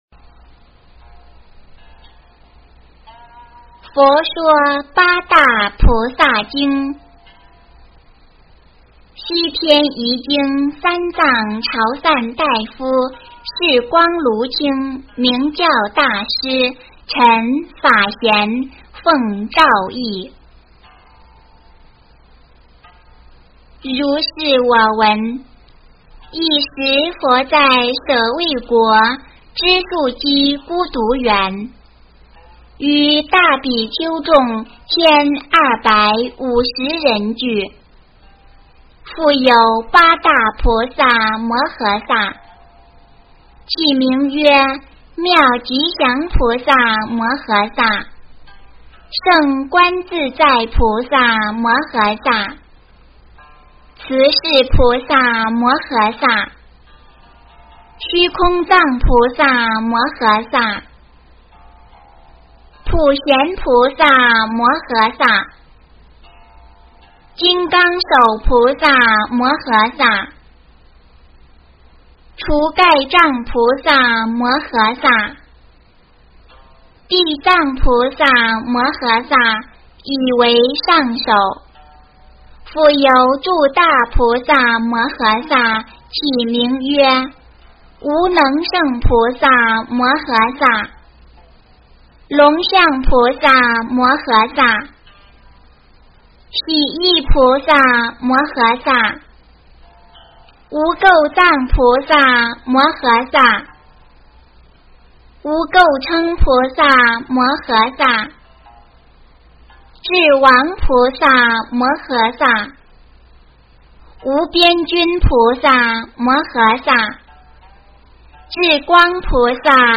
诵经
佛音 诵经 佛教音乐 返回列表 上一篇： 圣无量寿决定光明王陀罗尼 下一篇： 往生咒 相关文章 职场26留住人才的方法--佛音大家唱 职场26留住人才的方法--佛音大家唱...